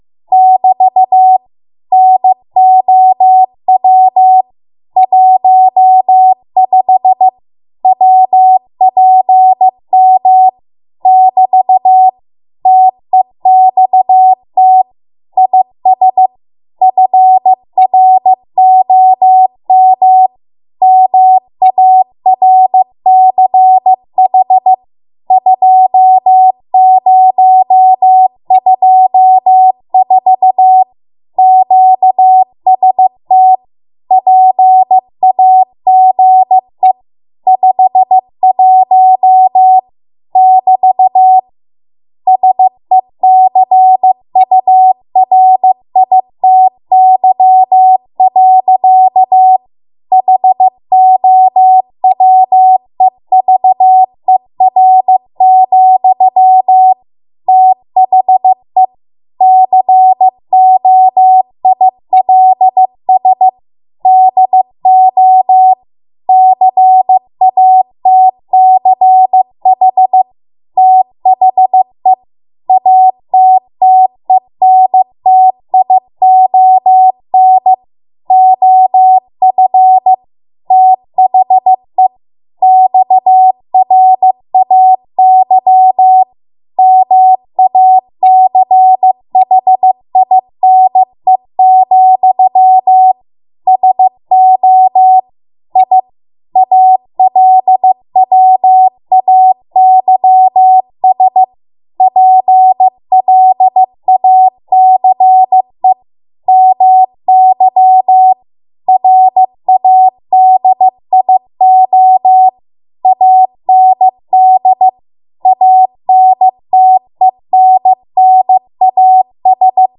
15 WPM Code Practice Archive Files
Listed here are archived 15 WPM W1AW code practice transmissions for the dates and speeds indicated.
You will hear these characters as regular Morse code prosigns or abbreviations.